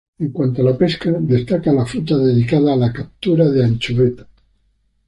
pes‧ca
Pronúnciase como (IPA)
/ˈpeska/